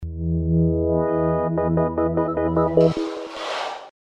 Index of /phonetones/unzipped/LG/LN272-Rumor-Reflex (ic)/Power Up-Down
LG_Startup2.mp3